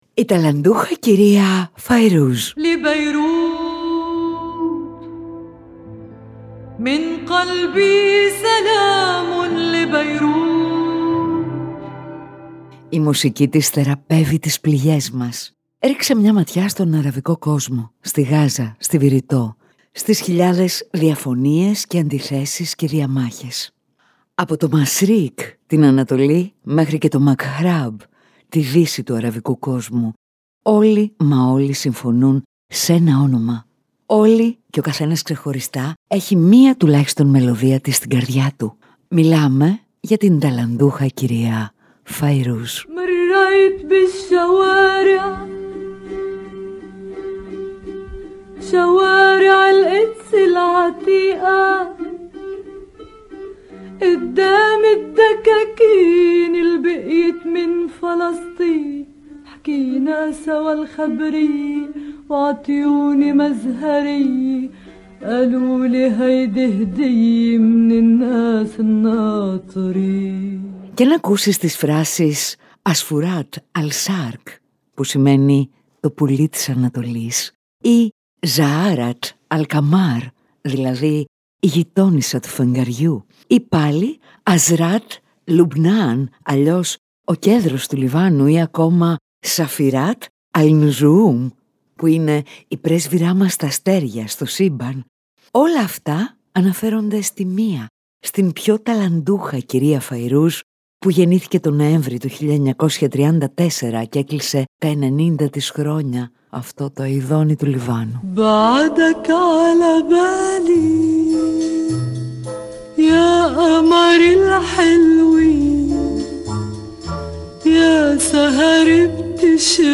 Ιστορίες, αφηγήσεις και σχόλια για να συστηθούμε καλύτερα.